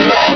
pokeemerald / sound / direct_sound_samples / cries / bagon.aif
-Replaced the Gen. 1 to 3 cries with BW2 rips.